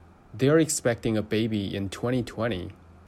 2020-sentence-pronunciation.mp3